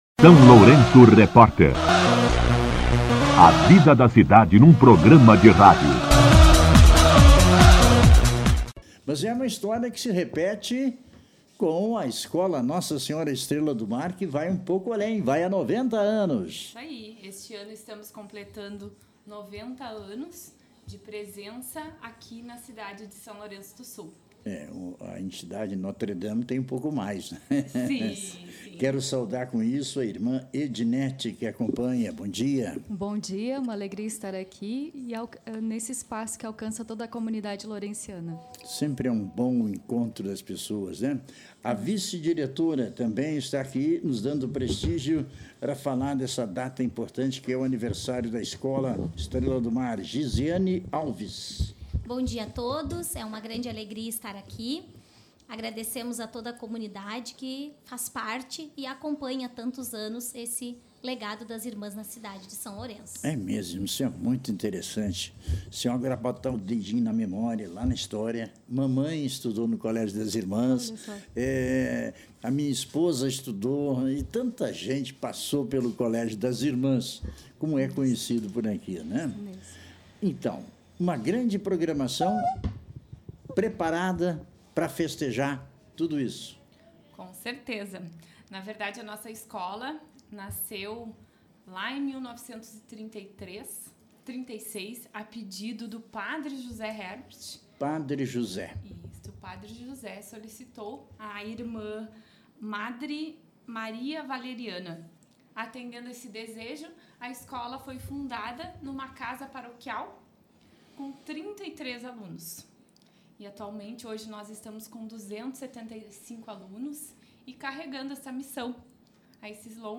Entrevista com a diretoria da Escola Estrela do Mar